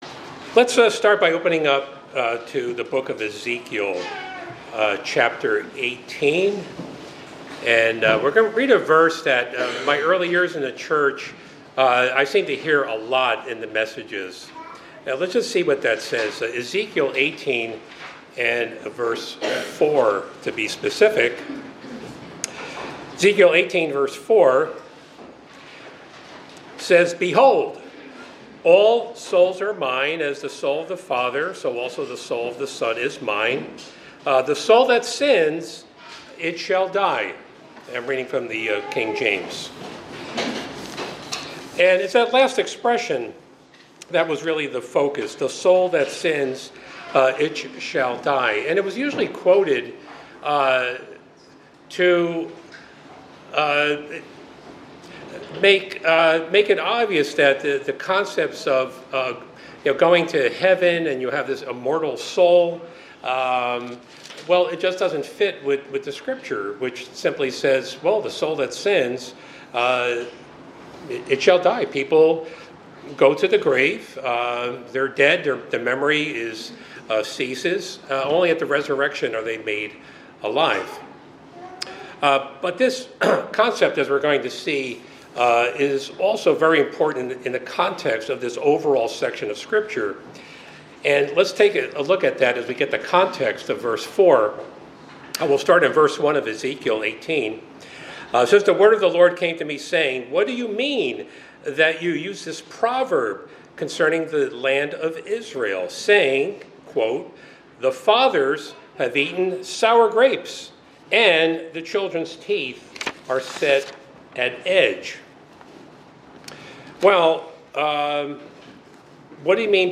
This message centers on the biblical principle of personal responsibility before God, using Ezekiel 18 to correct the mistaken belief that individuals are punished for the sins of their parents. Acknowledging that parents can strongly influence their children, the sermon stresses that influence is not an excuse, and repentance can change the outcome for anyone who turns from sin.
Given in Hartford, CT